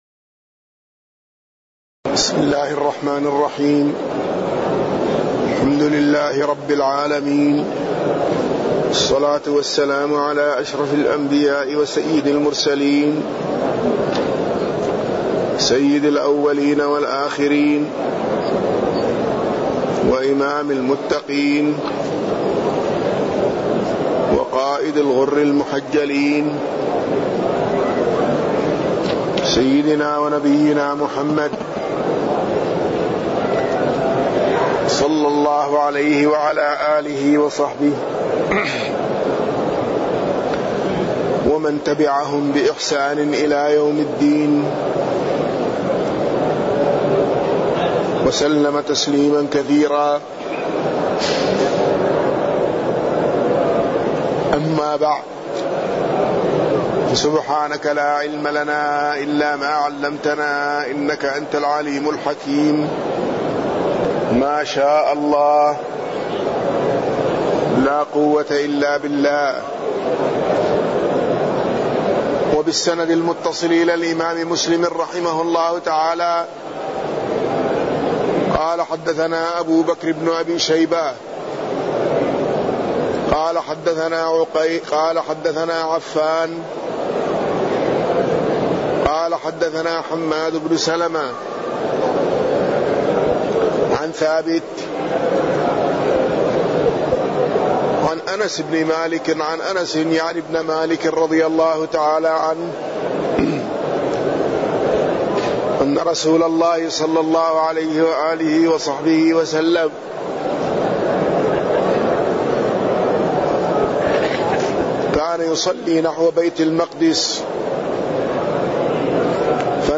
تاريخ النشر ٢٢ ربيع الثاني ١٤٢٩ هـ المكان: المسجد النبوي الشيخ